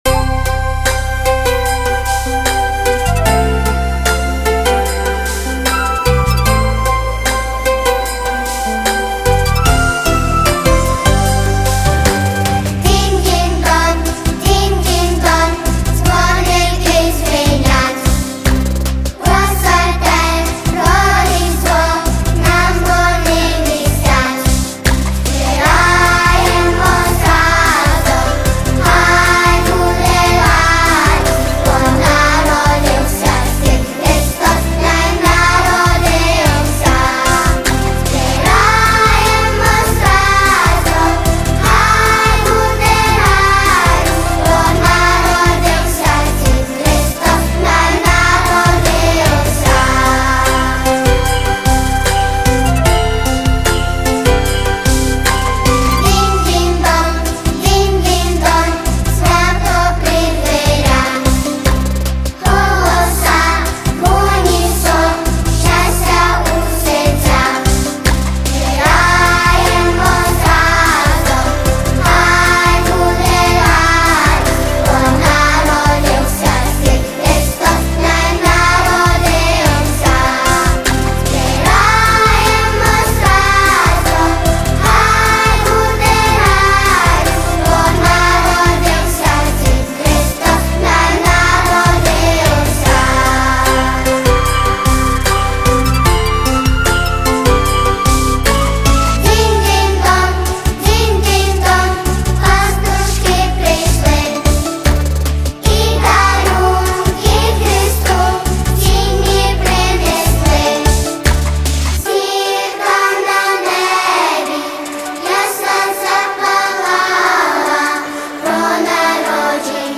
Різдвяна пісня |Колядка 2025